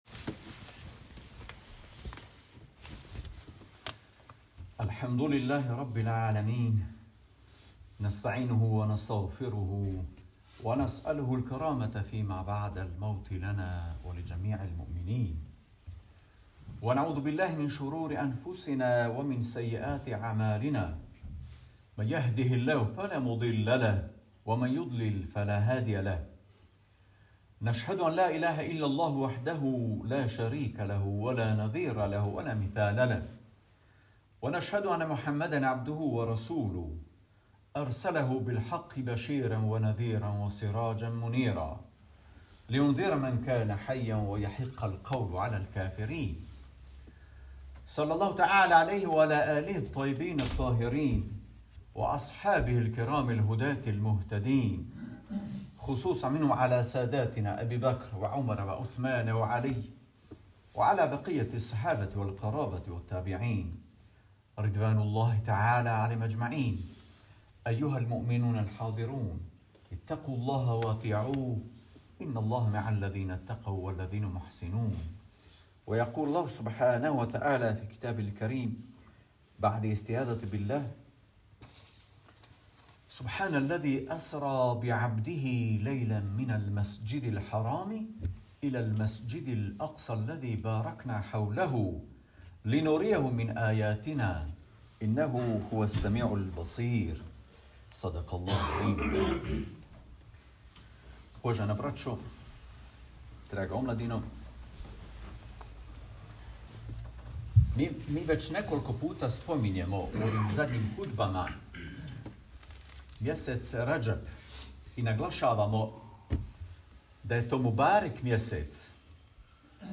Hutba: Miradž je poklon poslaniku